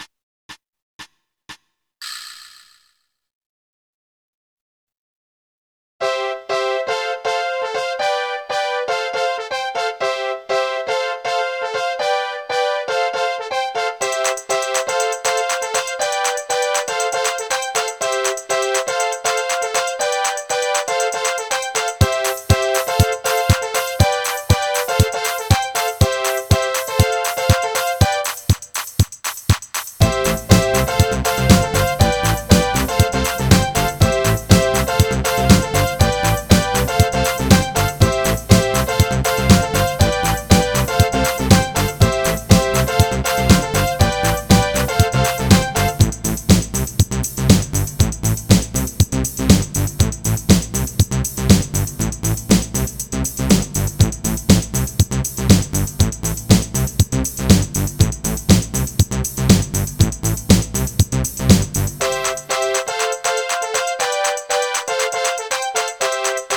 固件firm230328中，增加了新的“舞曲”功能，固件中给内置了30+首劲爆舞曲。
舞曲片段6